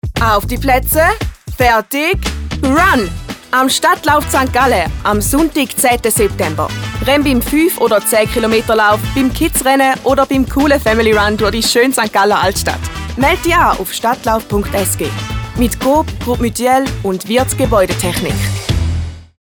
Radiospot